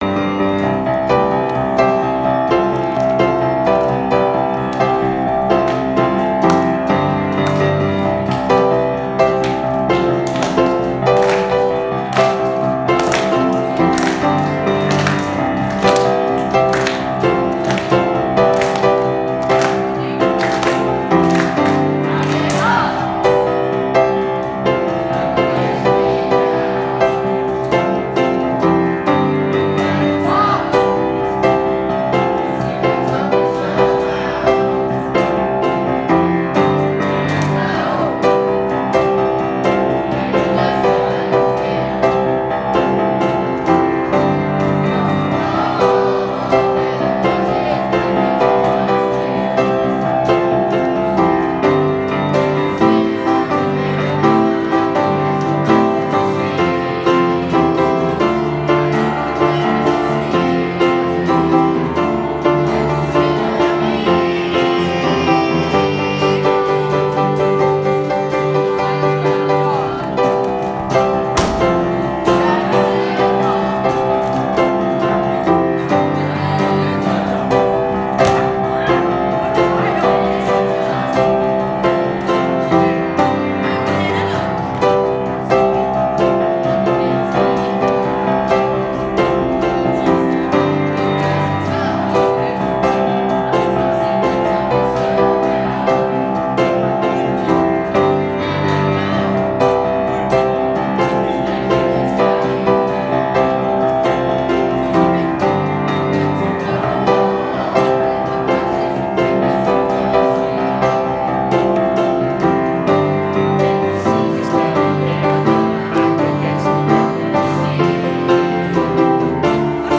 Lansdowne School Choir